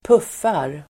Uttal: [²p'uf:ar]